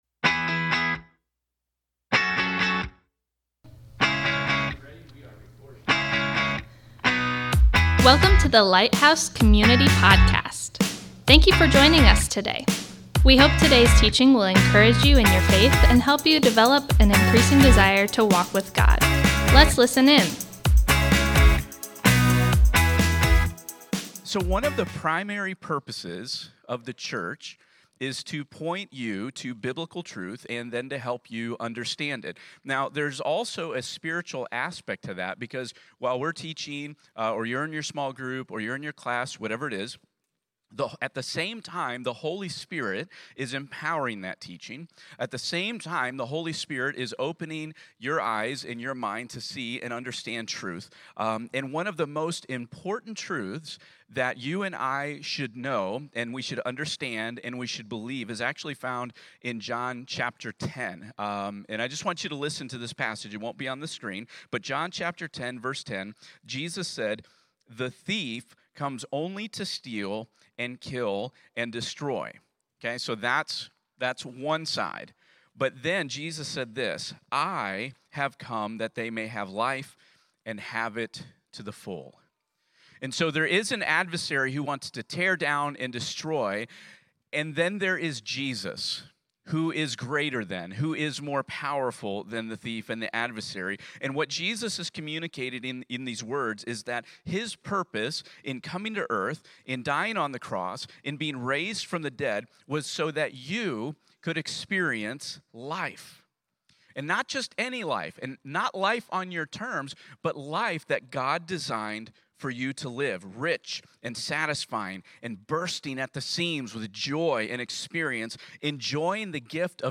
Thank you for joining us today as we come together to worship!